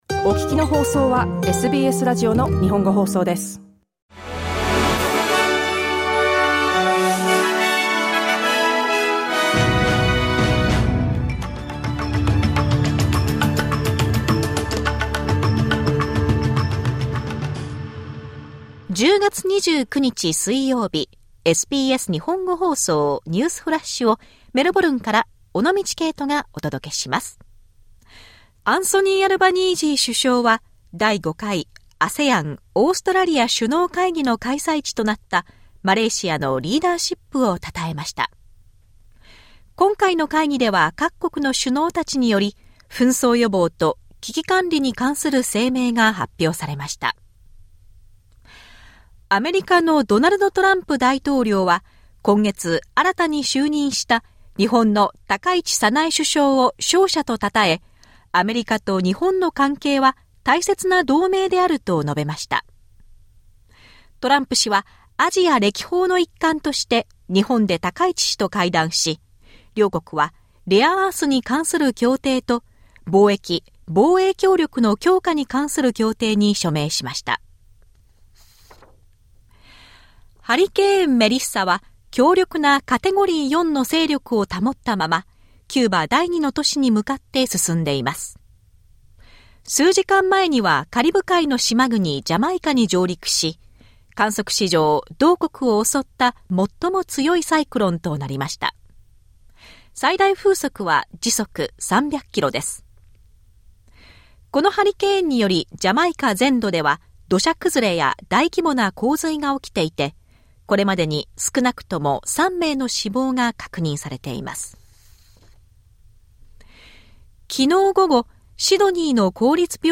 SBS日本語放送ニュースフラッシュ 10月29日 水曜日